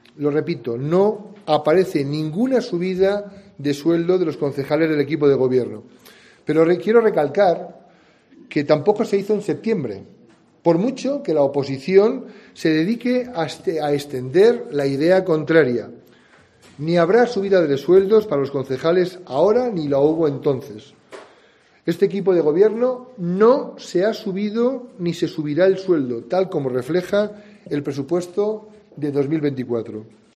José Mazarías, alcalde de Segovia, sobre las retribuciones del equipo de gobierno